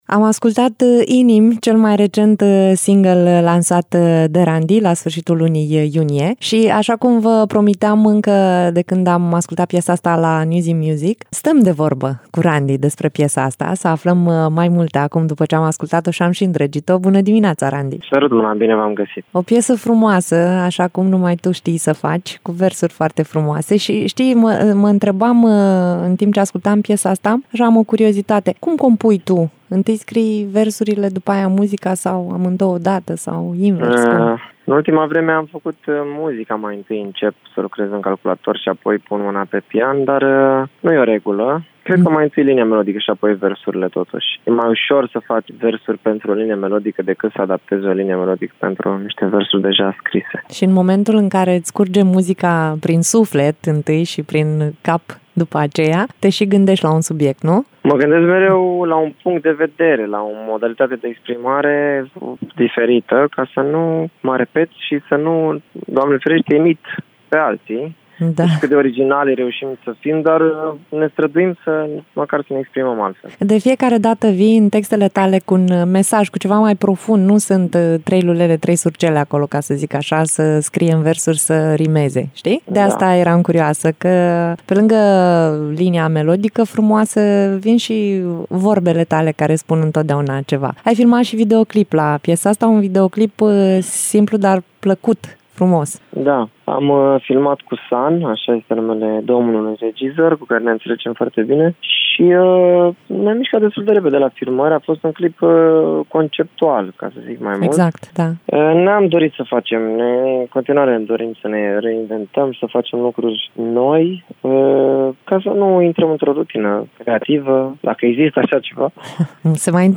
Interviu-Randi.mp3